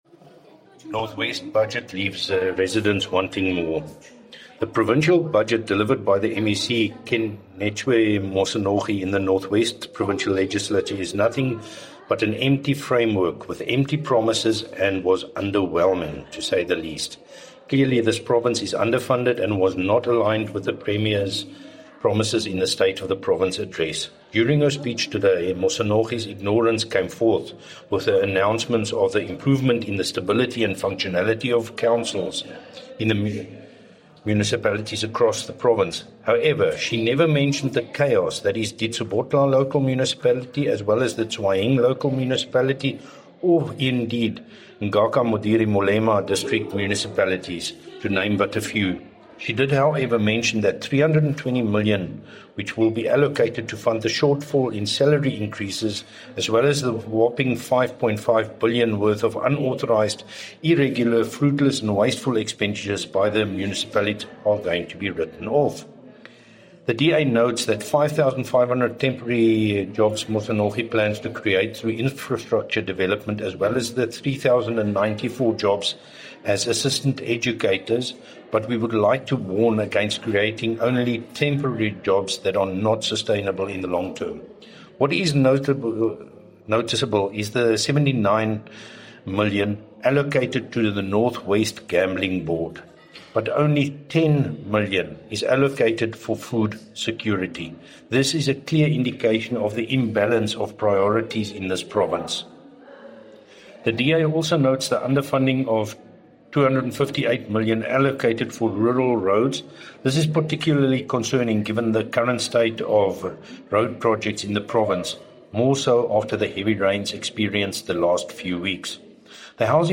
Issued by Wolfgang Wallhorn – DA Spokesperson on Finance in the North West Provincial Legislature
Note to Broadcasters: Please find attached soundbites in